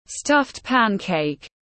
Bánh cuốn tiếng anh gọi là stuffed pancake, phiên âm tiếng anh đọc là /ˈstʌft ˈpæn.keɪk/
Stuffed pancake /ˈstʌft ˈpæn.keɪk/